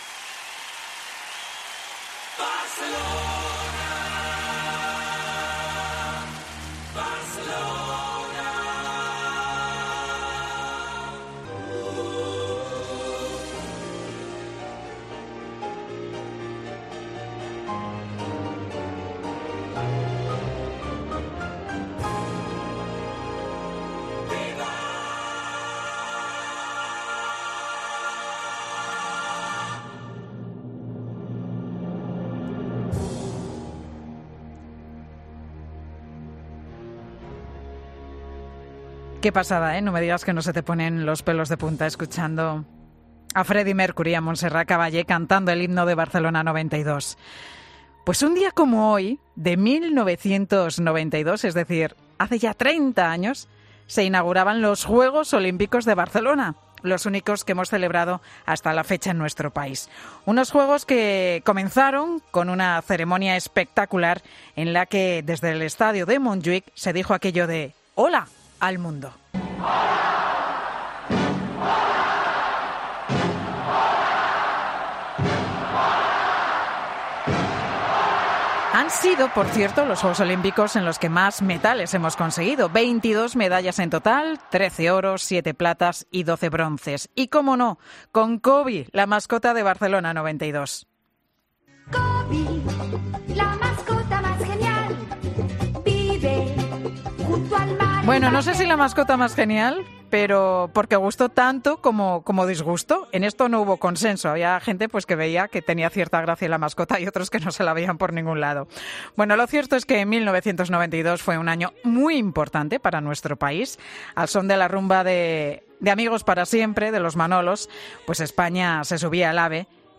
La imagen del estadio Olímpico de Barcelona llegaba a todos los rincones del mundo, y con ella esta música que suena de fondo.